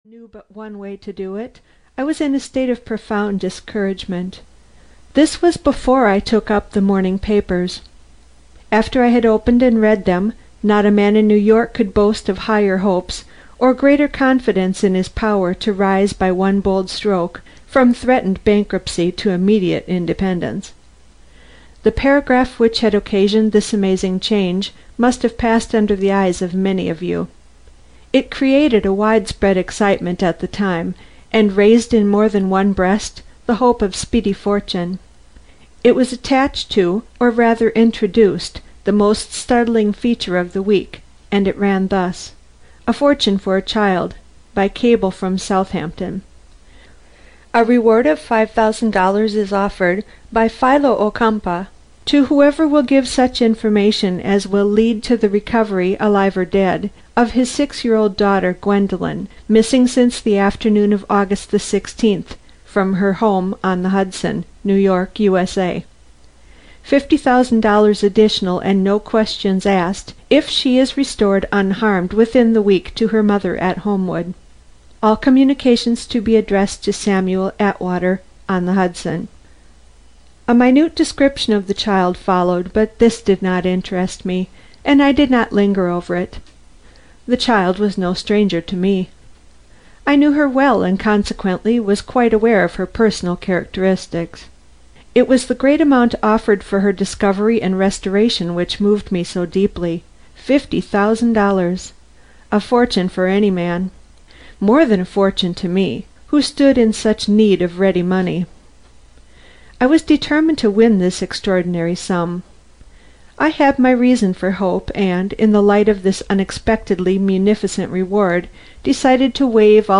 The Millionaire Baby (EN) audiokniha
Ukázka z knihy